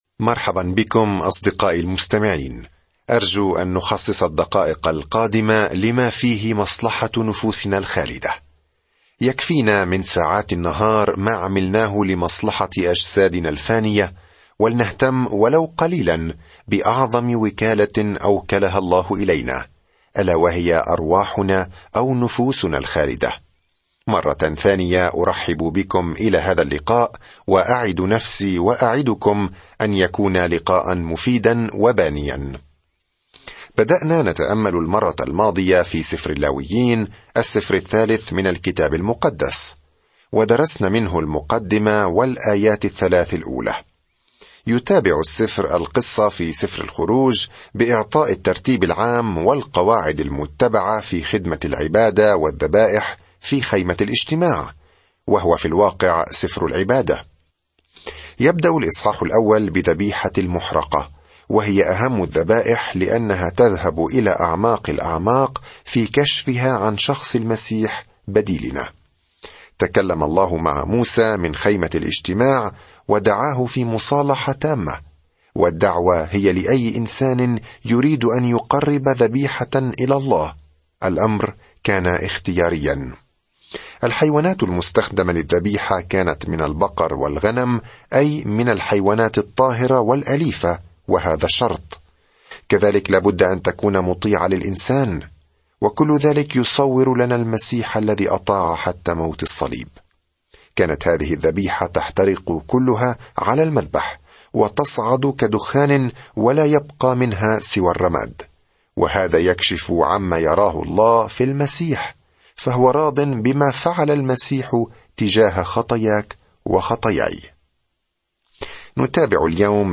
في العبادة والتضحية والتبجيل، يجيب سفر اللاويين على هذا السؤال بالنسبة لإسرائيل القديمة. سافر يوميًا عبر سفر اللاويين وأنت تستمع إلى الدراسة الصوتية وتقرأ آيات مختارة من كلمة الله.